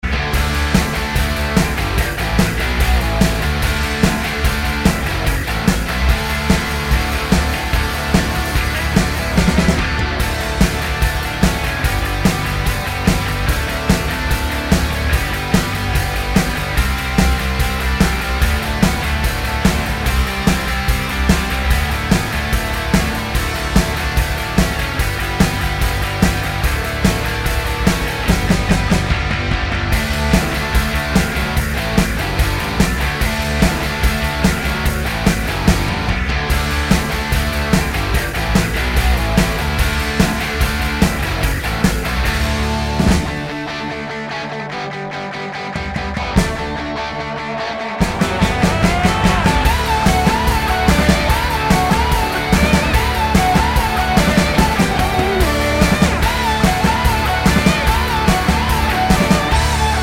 no Backing Vocals Punk 3:11 Buy £1.50